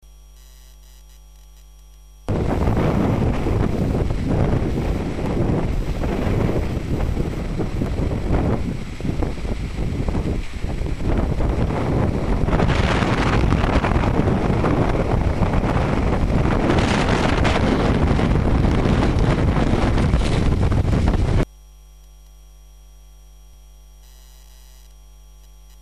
Corn ground in Countryside